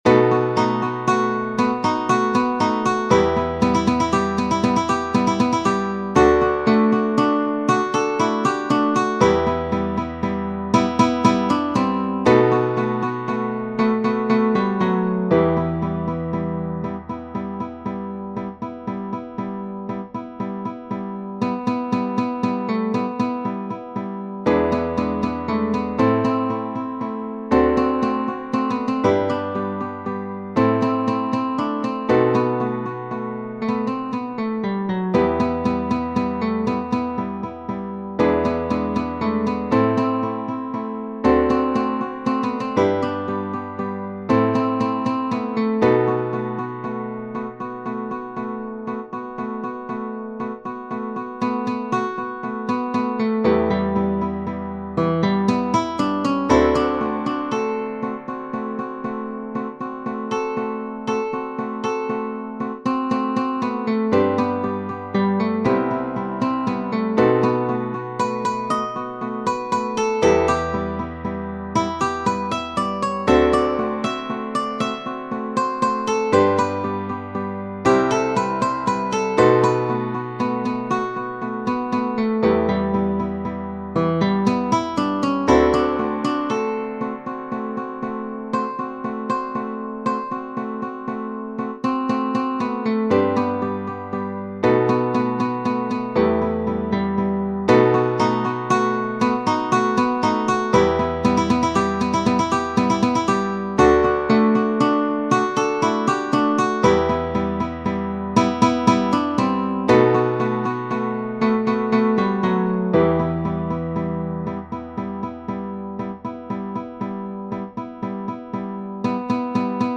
Genere: Folk
in stile son huasteco o huapango